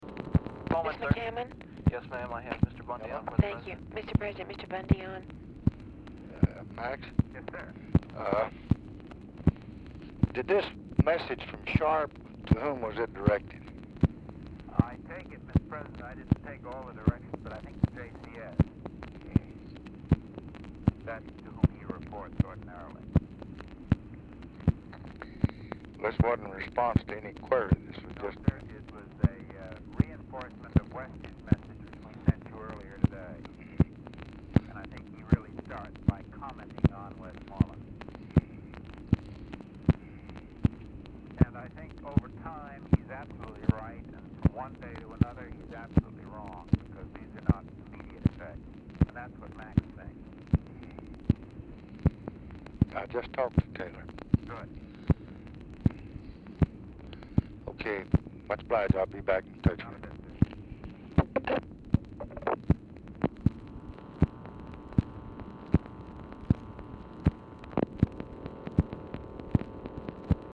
Telephone conversation # 9341, sound recording, LBJ and MCGEORGE BUNDY, 12/27/1965, 9:07PM | Discover LBJ
Format Dictation belt
Location Of Speaker 1 LBJ Ranch, near Stonewall, Texas
Specific Item Type Telephone conversation Subject Defense Diplomacy Vietnam